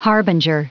Prononciation du mot harbinger en anglais (fichier audio)
Prononciation du mot : harbinger